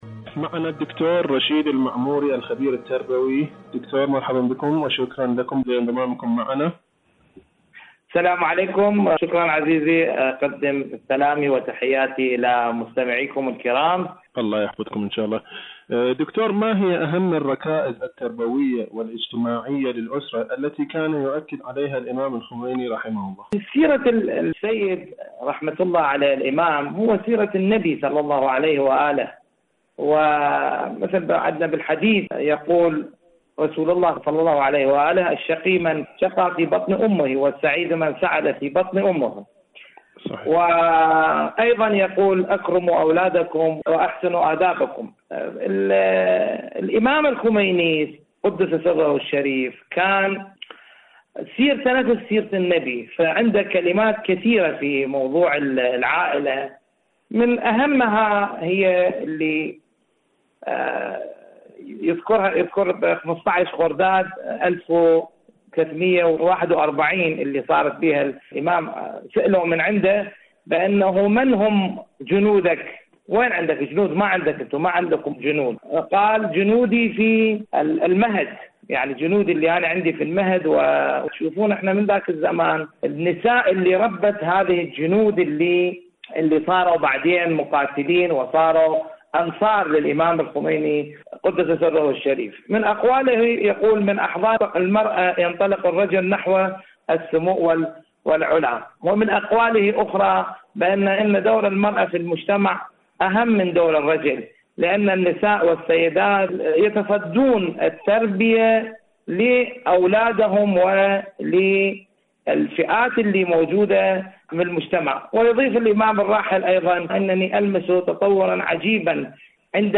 مقابلة
إذاعة طهران-معكم على الهواء: مقابلة إذاعية